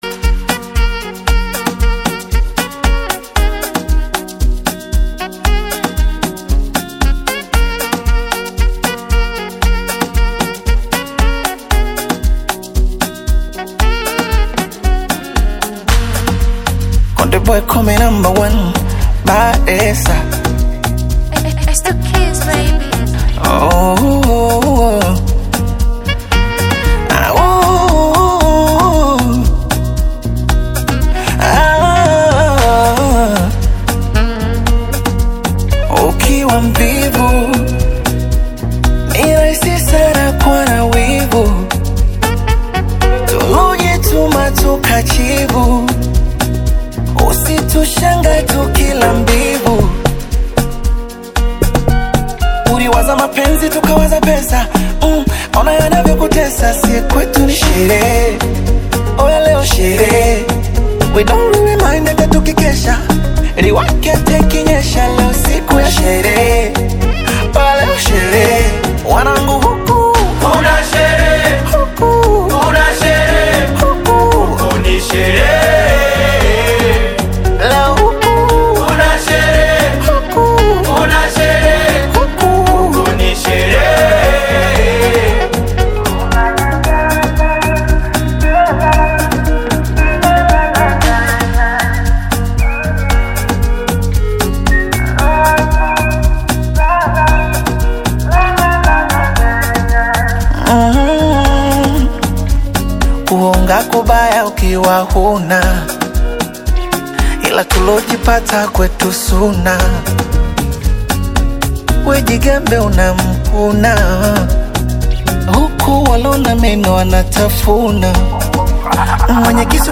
indicating that the song is likely festive and upbeat.